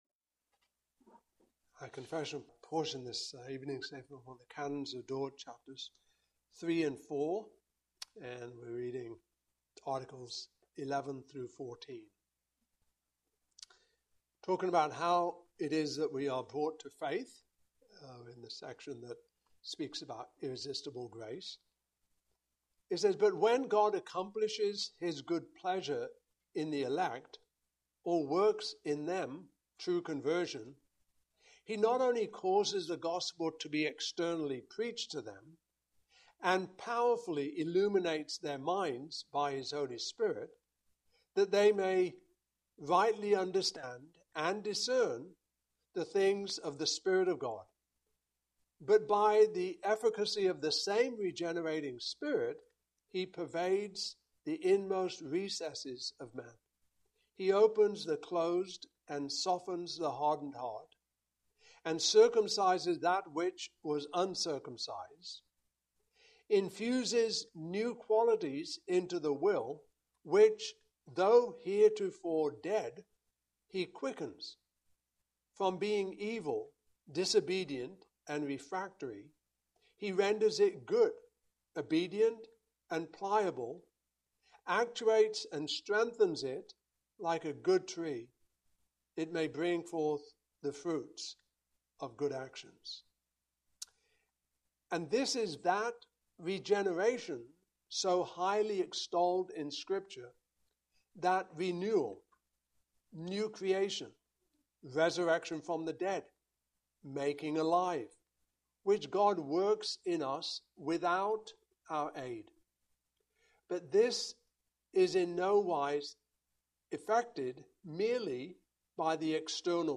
Service Type: Evening Service Topics: Article 11-14 , Head 3/4 , Unconditional Election